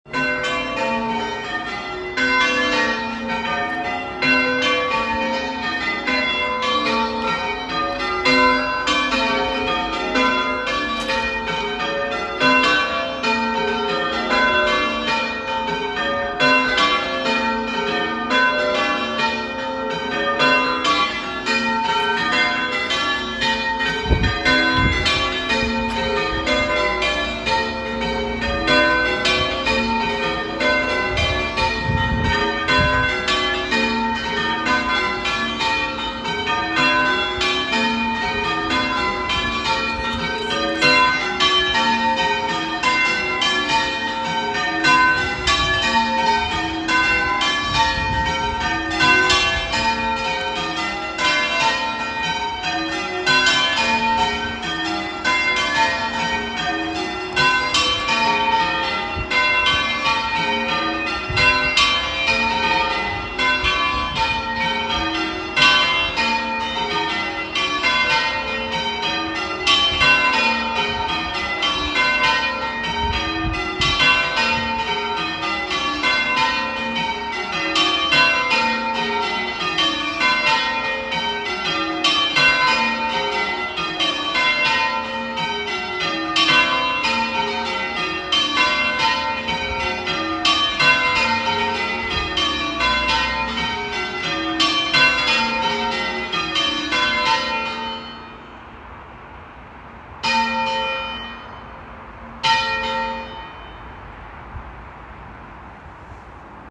Bells Archive: 2024
The buttons below enable you to download mp3 audio files, with recordings of our bells.